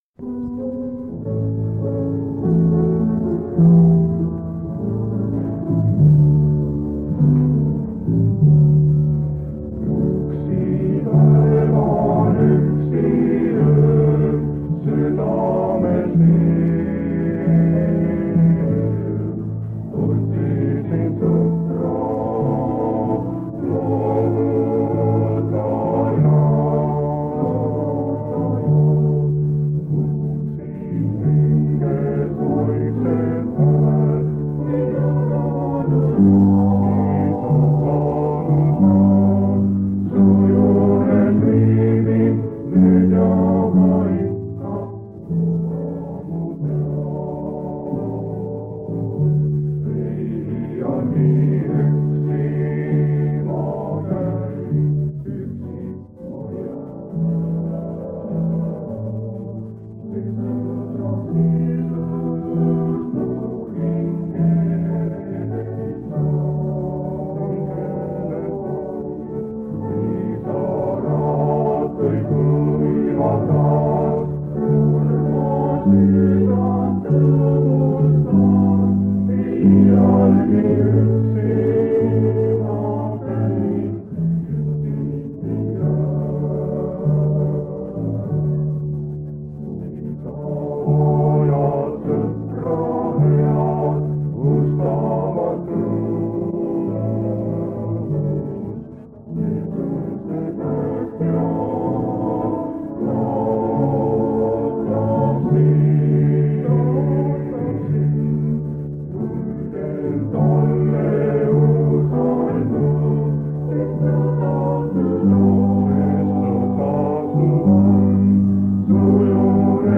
Koosolekute helisalvestused
Reede õhtul ja hingamispäeva päeval 8-9 oktoobril 1976 aastal Tallinna adventkoguduse 79. aastapäeval